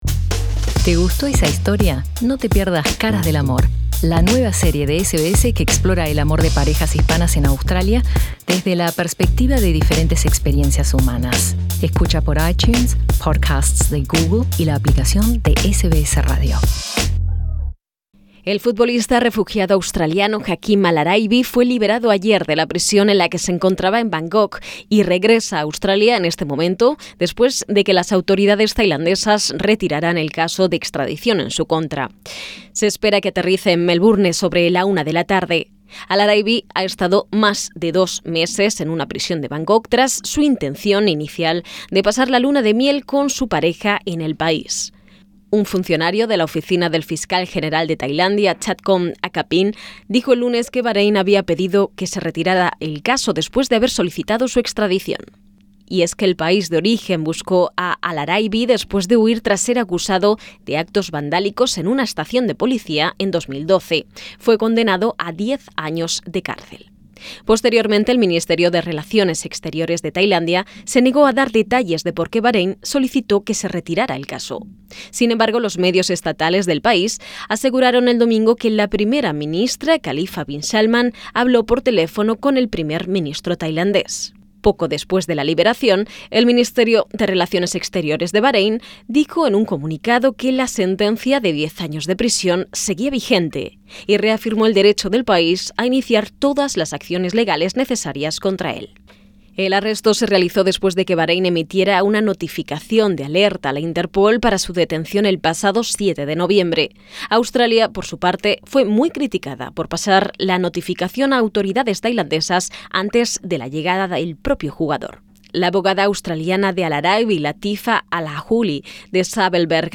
La esposa y la familia de Hakeem al-Araibi se están preparando para darle la bienvenida a su casa en Melbourne después de que el futbolista fue liberado de una prisión tailandesa. Presiona el enlace para escuchar el informe preparado por SBS en español.